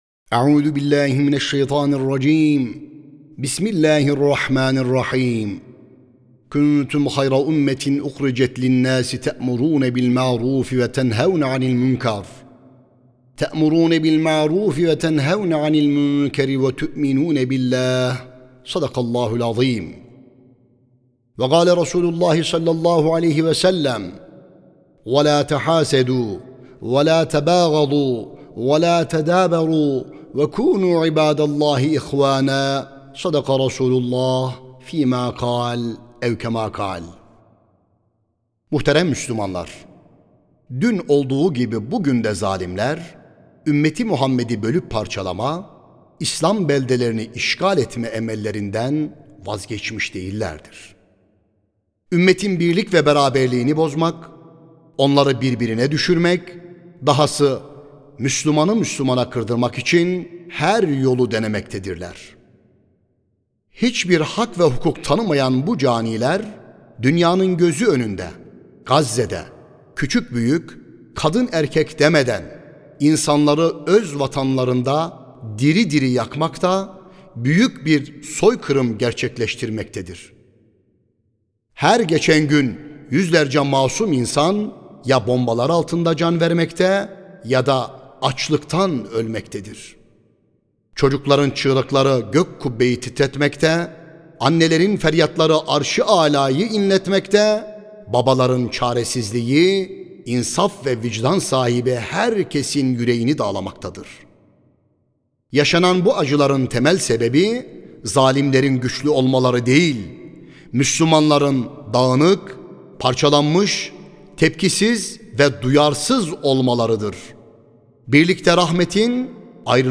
11 NİSAN CUMA HUTBESİ KONUSU
Sesli Hutbe (Ümmet Olmak, Birlik Olmaktır).mp3